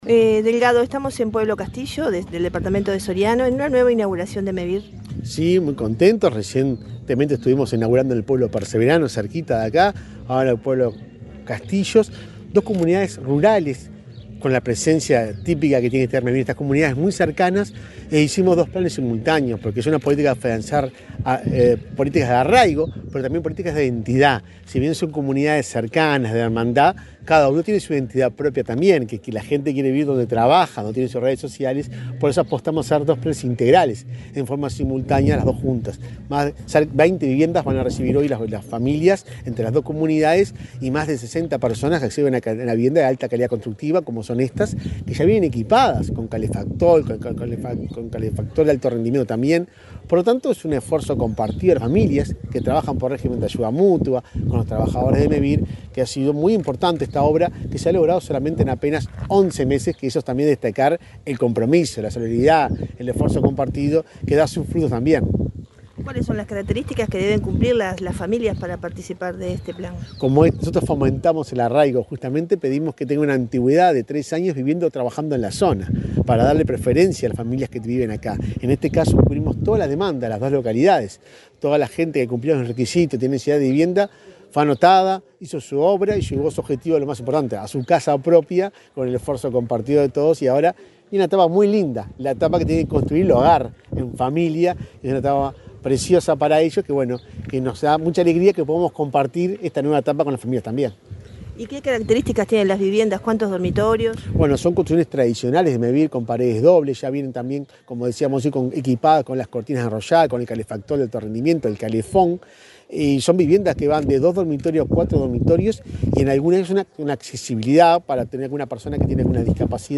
Entrevista al presidente de Mevir, Juan Pablo Delgado, en Pueblo Castillo
Entrevista al presidente de Mevir, Juan Pablo Delgado, en Pueblo Castillo 20/11/2024 Compartir Facebook X Copiar enlace WhatsApp LinkedIn El presidente de Mevir, Juan Pablo Delgado, dialogó con Comunicación Presidencial, antes de participar en la inauguración de viviendas rurales en Pueblo Castillo, departamento de Soriano.